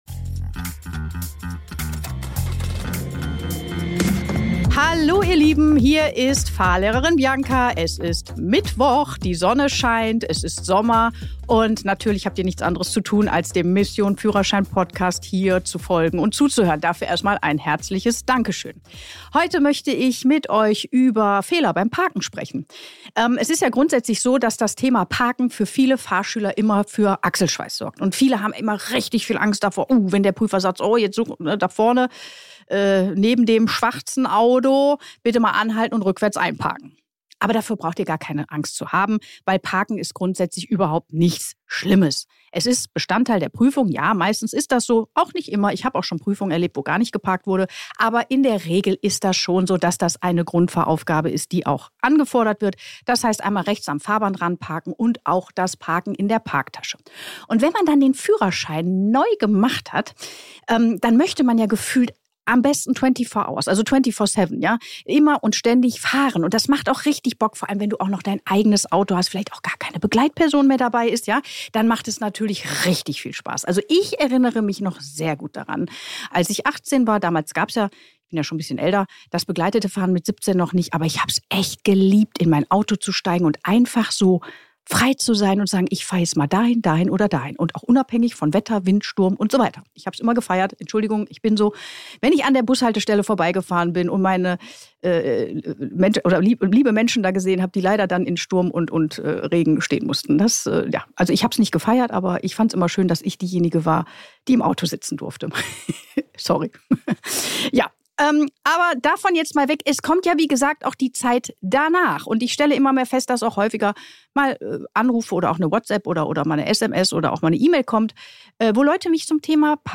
In der heutigen Solo-Episode spreche ich über ein Thema, das nach der Prüfung oft unterschätzt wird – Parken .